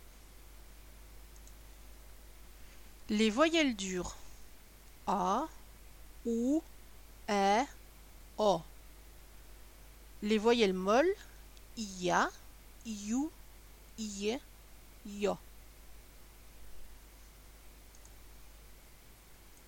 1-En ce qui concernent les voyelles, j’ai oublié de préciser qu’elles sont soit dures: а, у, э, о
soit molles: я, ю, е, ё.
voyelles-dures-et-molles.mp3